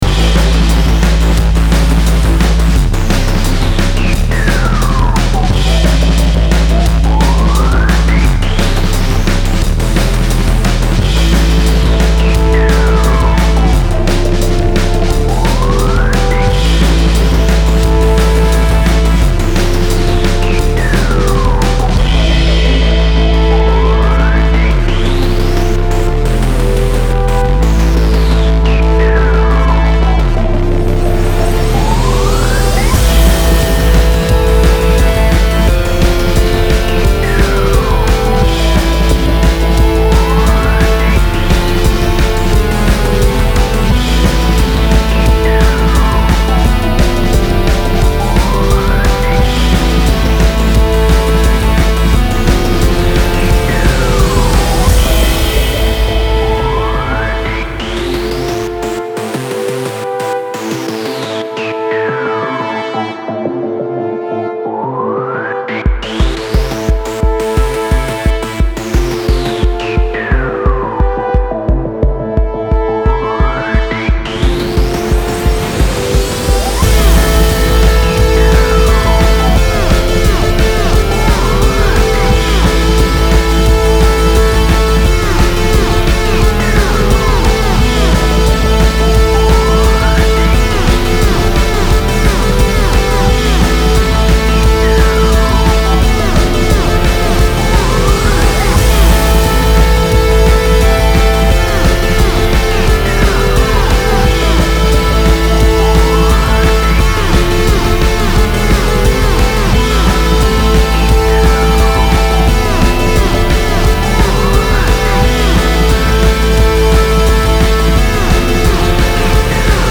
Style Style EDM/Electronic
Mood Mood Driving, Intense
Featured Featured Bass, Drums, Synth
BPM BPM 175
Oh, and this ones a seamless looping track.